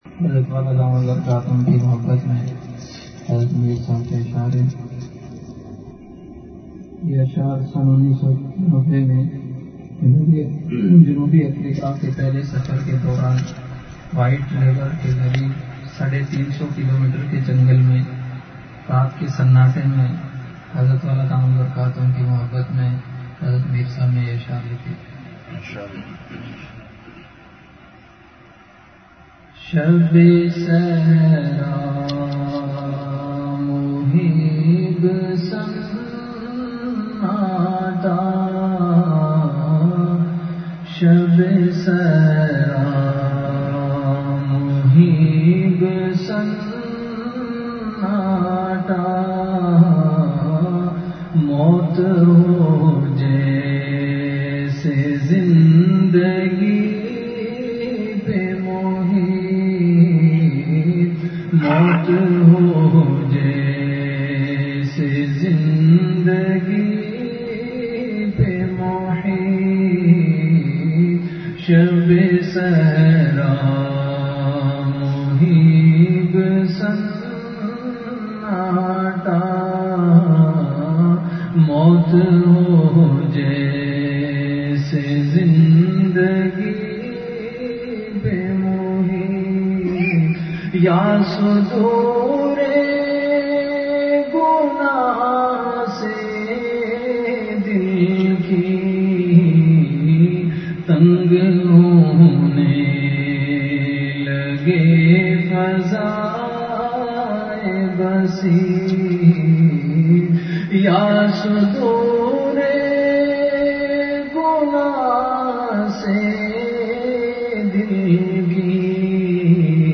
Delivered at Khanqah Imdadia Ashrafia.